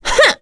Priscilla-Vox_Attack2.wav